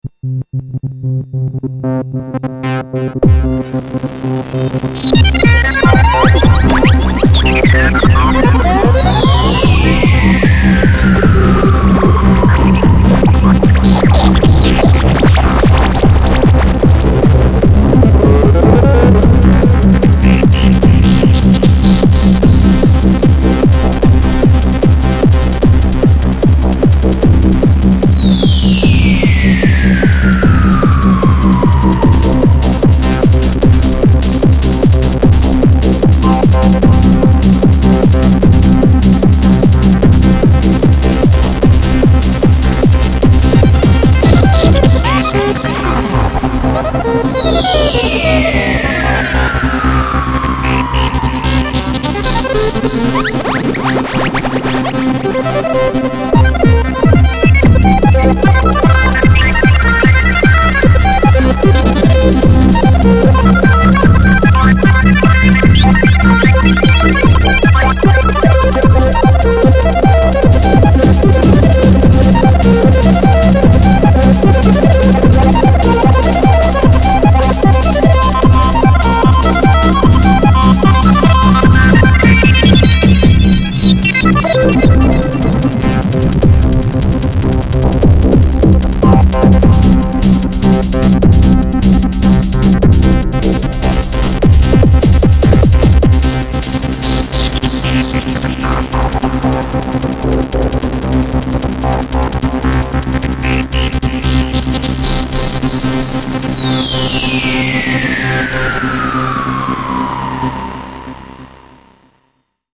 c'est du MS-20 Hardware, séquencé avec Fruityloops en conjonction d'un KENTON PRO2000
la plupart des sons sont samplés et traités soit direct sur la console (Reverb de ma TASCAM DM24), quelques effets ca et là, mais pas trop, le Fruity Compressor sur la grosse caisse, un autopan, un Delay Ping-Pong (calé a 3 croches...)
la ligne mélodique (qui fait aussi les bruitages zarbi....) est séquencée mais triturée en temps réel